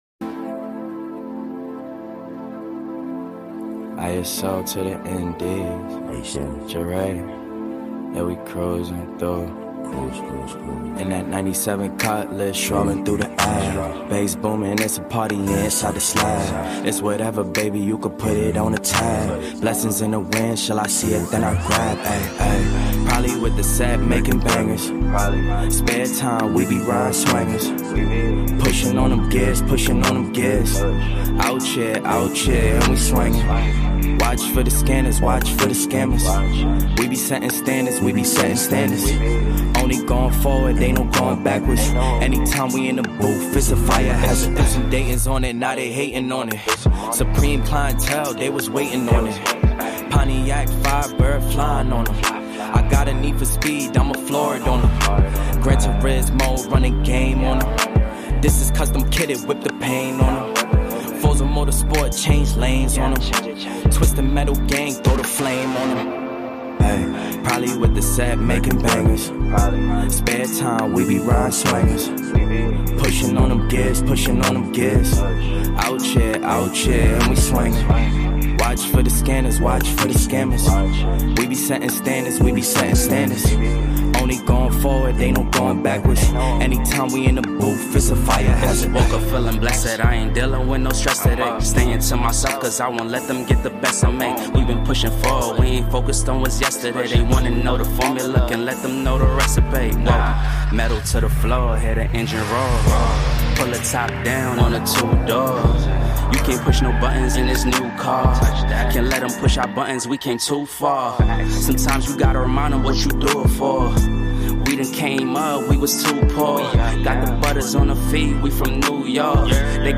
The PHP Podcast streams the recording of this podcast live, typically every Thursday at 3 PM PT.